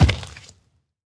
assets / minecraft / sounds / mob / zoglin / step4.ogg
step4.ogg